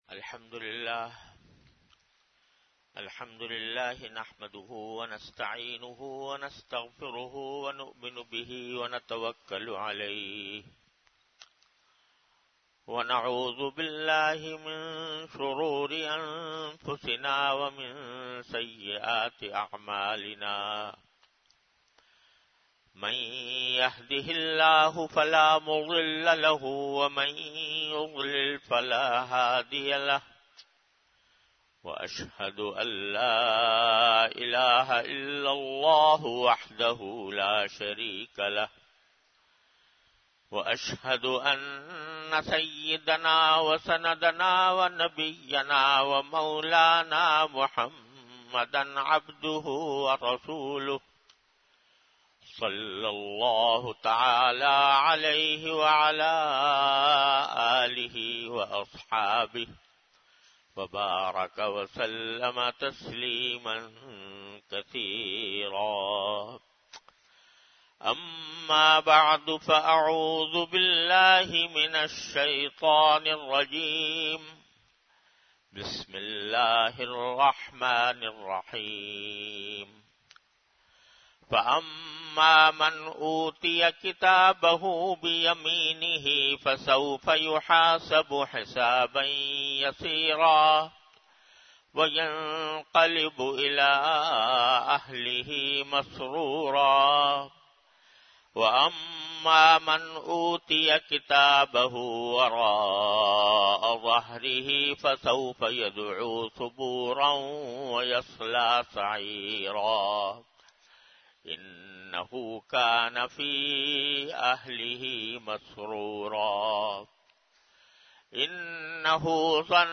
An Islamic audio bayan by Hazrat Mufti Muhammad Taqi Usmani Sahab (Db) on Tafseer. Delivered at Jamia Masjid Bait-ul-Mukkaram, Karachi.